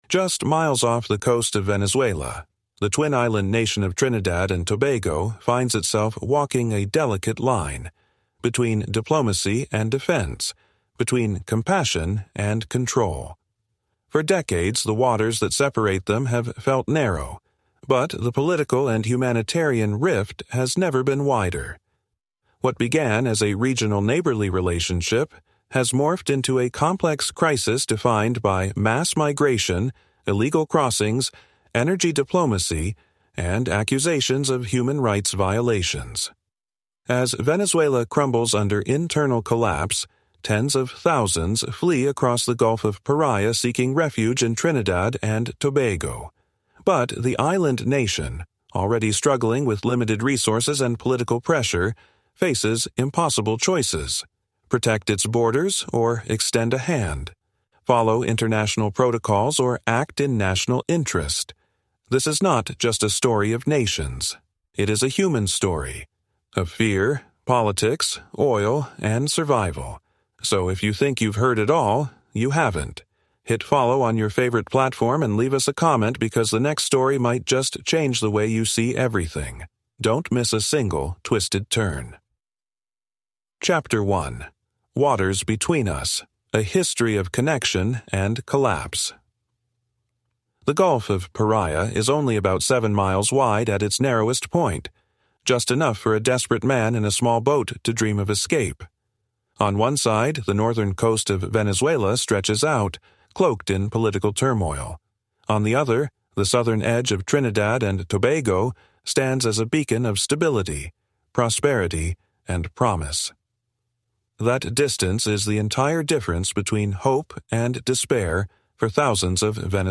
Major Worries (Dancehall Legend) is the electrifying story of the Spanish Town deejay whose razor‑sharp wit and social commentary reshaped dancehall, challenged Jamaican music norms, and echoed across the entire Caribbean region. Join our history experts and travel storytellers as we trace his journey from zinc‑fence sound‑system clashes to chart‑topping cassettes, revealing untold truths about colonization, Caribbean identity, and the enduring spirit of black history.